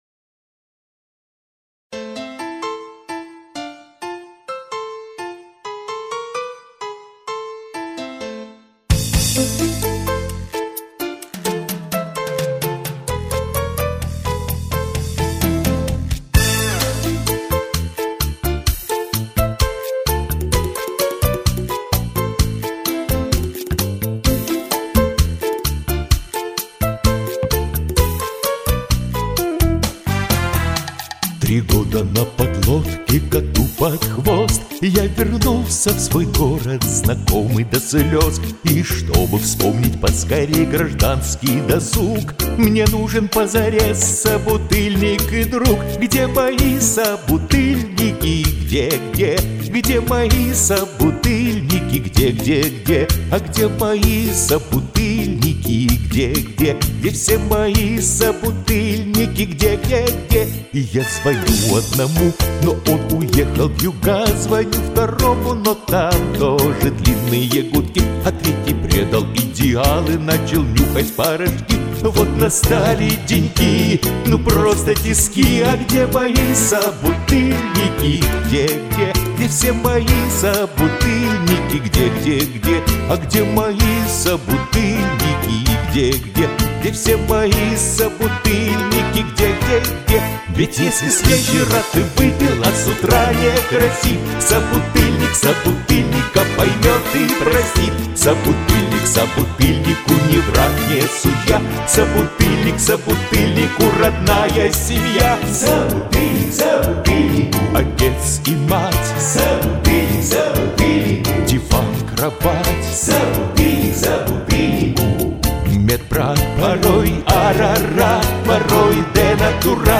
Азартно поёте!